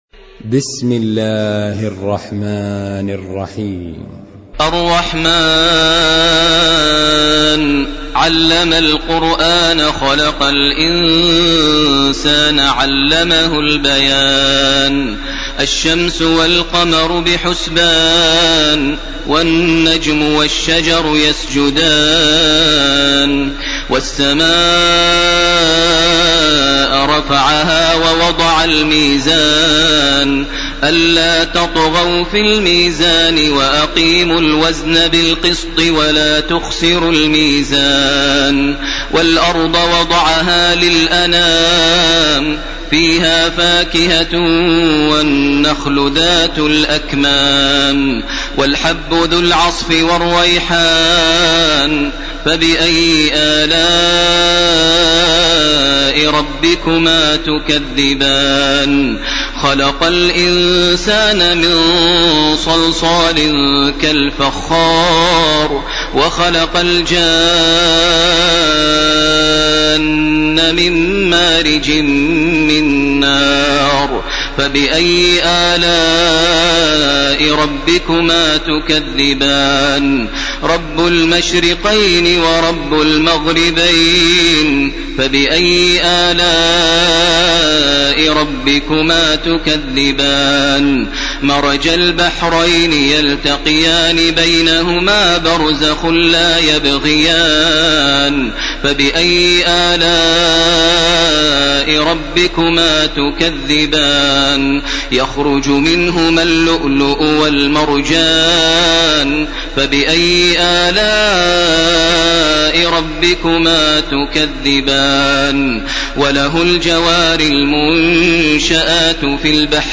ليلة 26 من رمضان 1431هـ سورة الرحمن وسورة الواقعة وسورة الحديد كاملة. > تراويح ١٤٣١ > التراويح - تلاوات ماهر المعيقلي